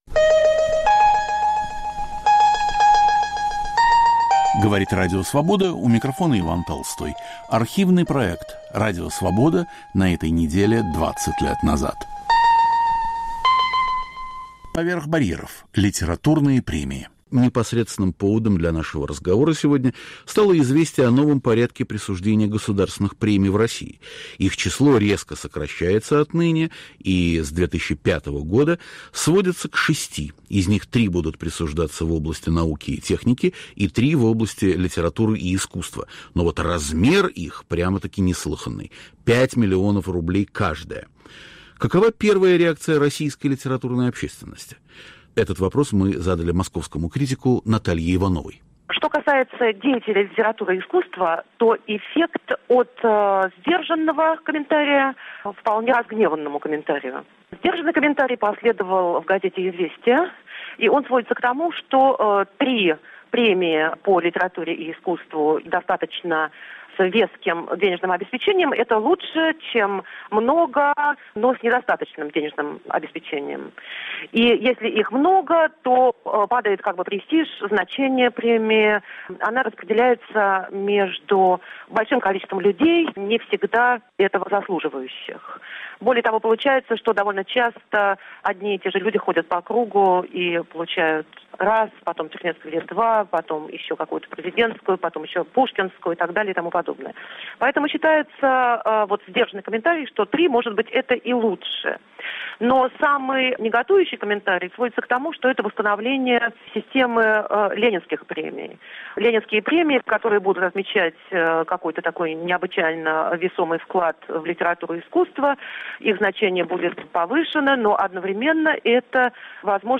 Прямой эфир о премиях в России и в мире. О новом порядке присуждения государственных премий.
Участвует писатель Яков Гордин. Автор и ведущий Иван Толстой.